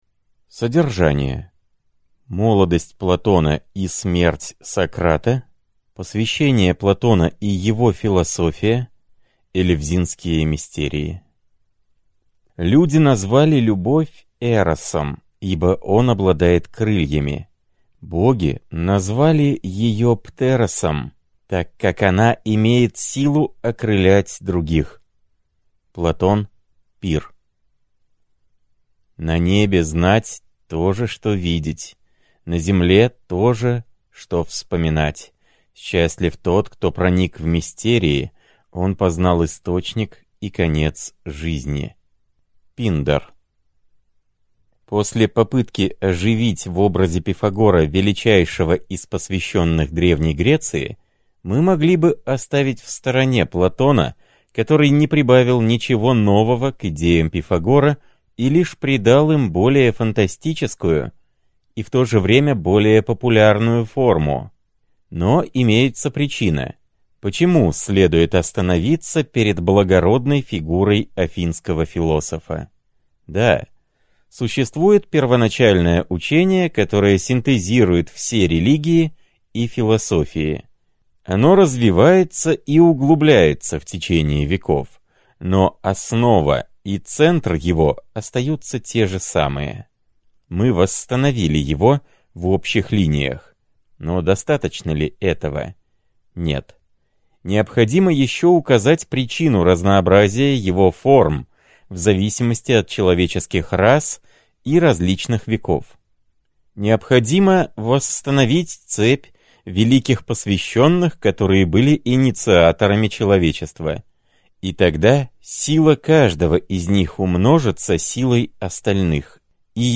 Аудиокнига Платон. Элевзинские Мистерии. Выпуск 7 | Библиотека аудиокниг